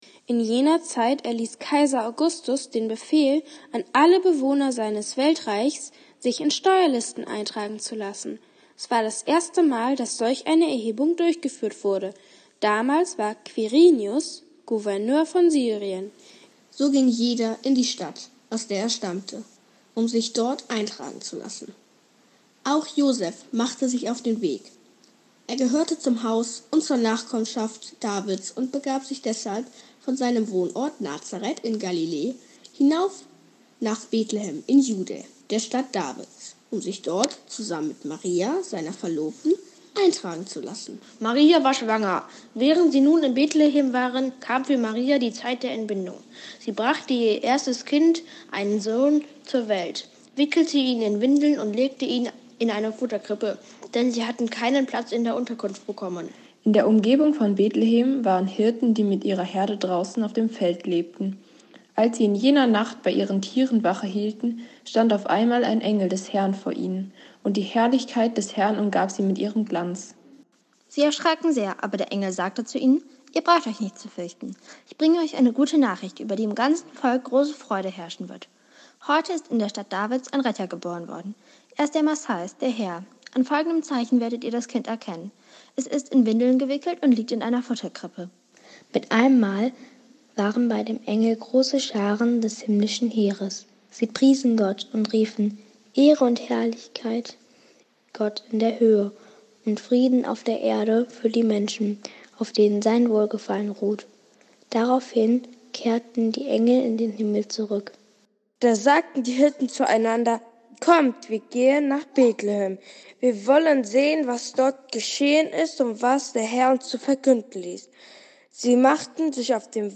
Hört die Weihnachtsgeschichte, gelesen von Jugendlichen aus der Gemeinde…